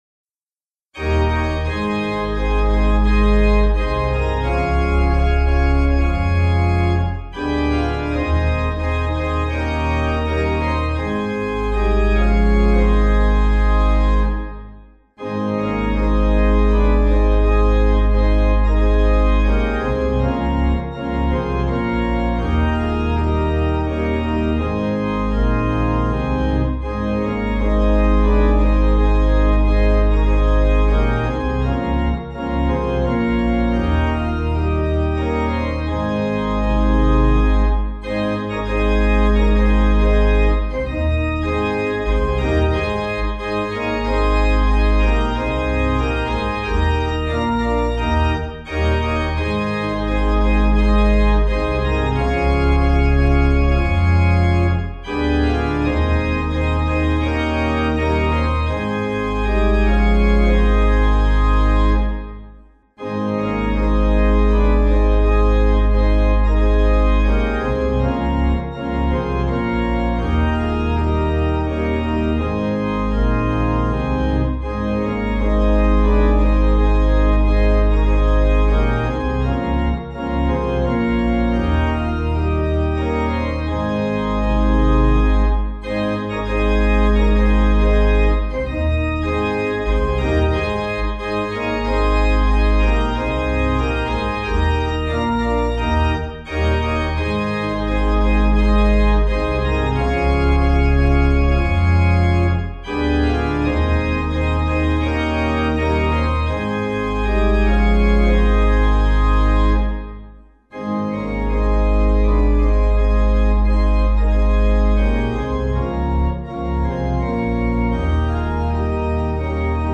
Instrumental accompaniment